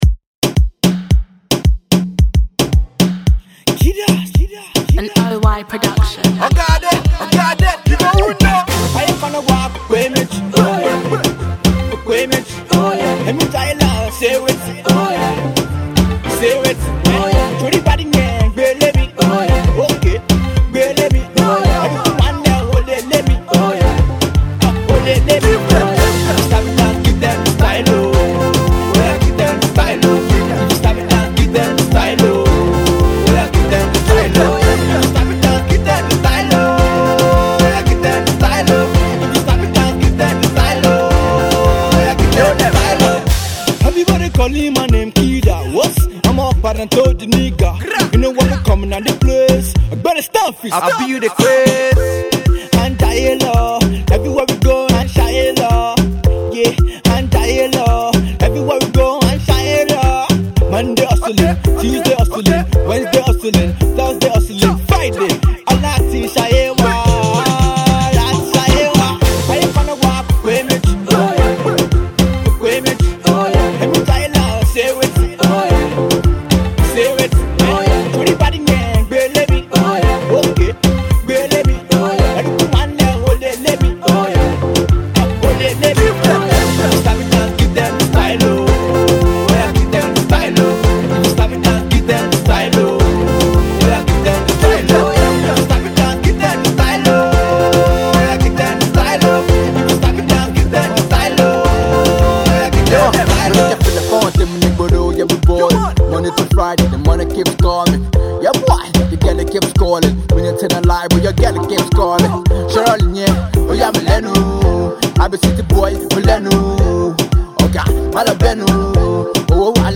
AudioPop
Galala inspired single